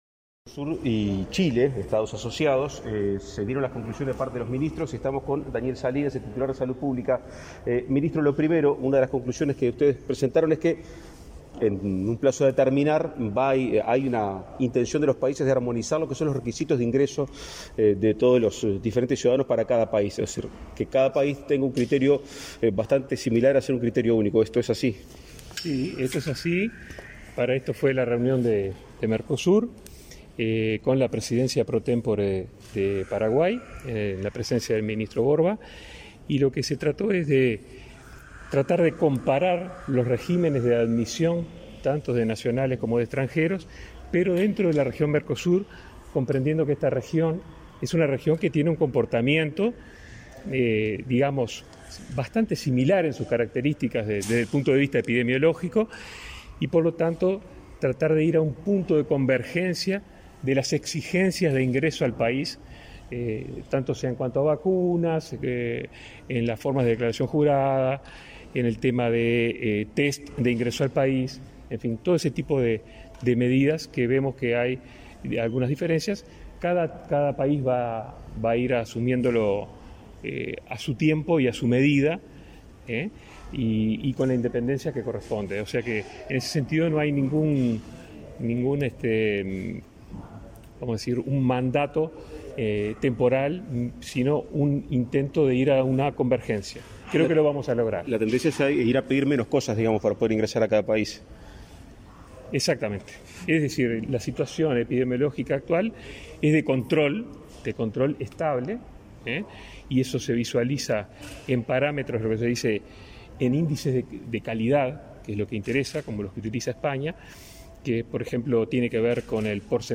Declaraciones a la prensa del ministro de Salud Pública, Daniel Salinas
Declaraciones a la prensa del ministro de Salud Pública, Daniel Salinas 22/03/2022 Compartir Facebook X Copiar enlace WhatsApp LinkedIn Los ministros de Salud de los países miembros del Mercado Común del Sur (Mercosur) se reunieron este 22 de marzo en Montevideo. Tras el encuentro, el ministro Salinas efectuó declaraciones a la prensa.